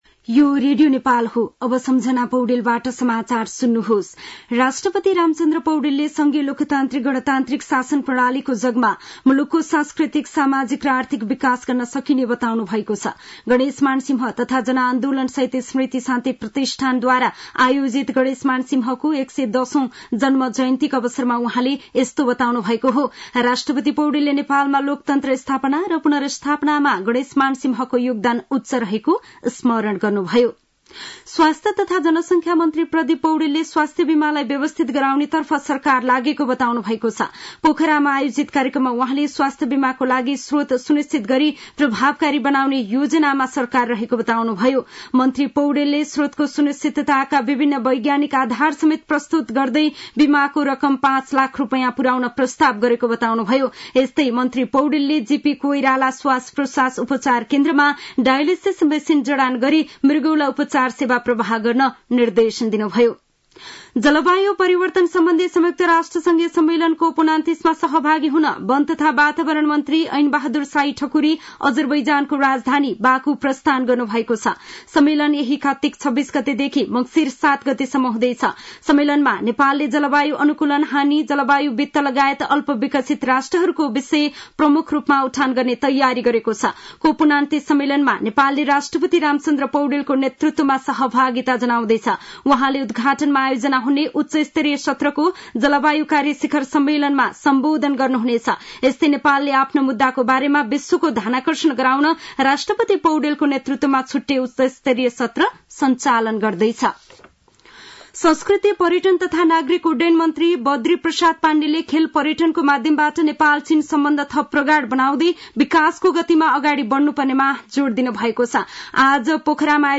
साँझ ५ बजेको नेपाली समाचार : २५ कार्तिक , २०८१